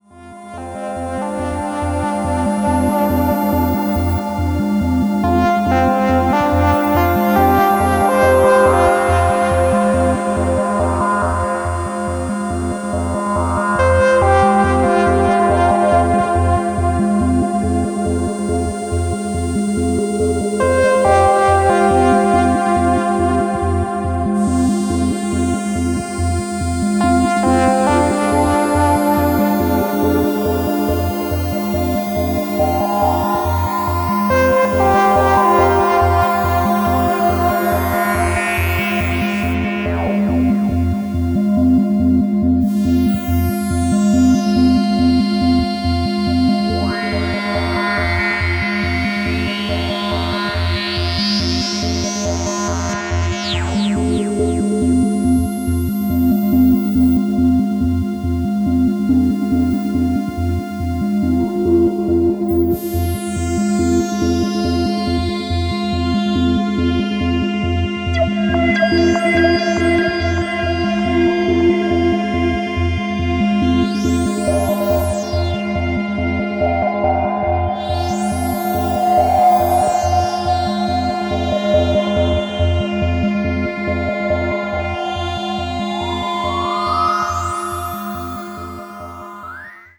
Electronix Techno Wave Pop